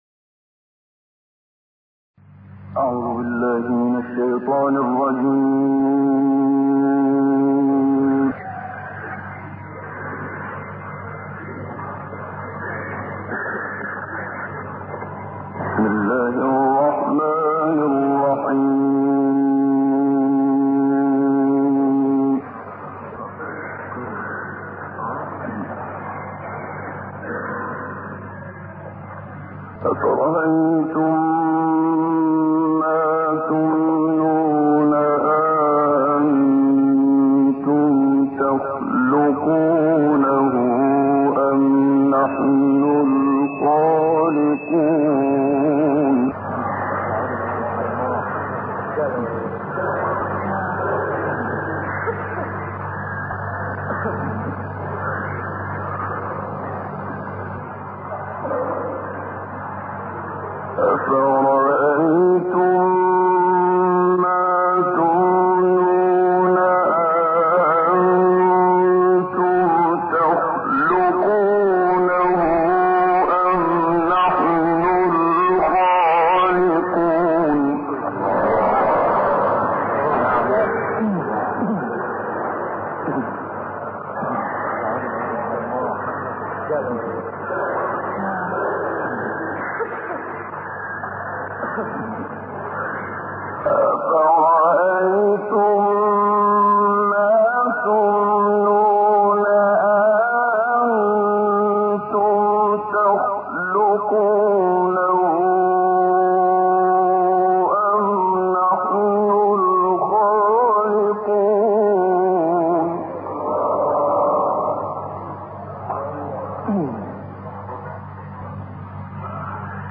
منشاوی واقعه 58 تا آخر،حدید1 تا 6 علق،حمد ، بقره 1 تا 2 در سوریه